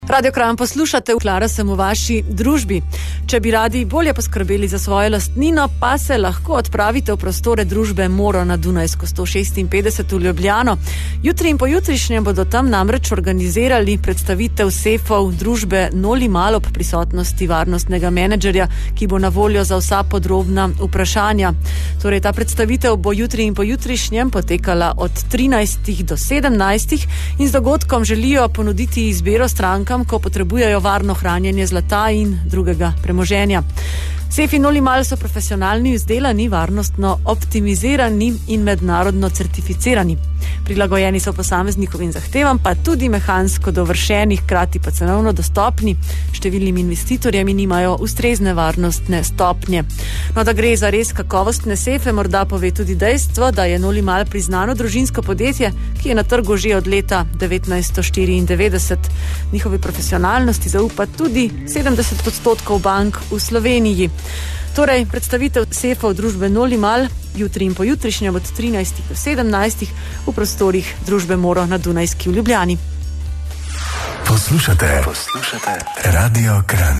O dogodku so poročali tudi na RA Kranj.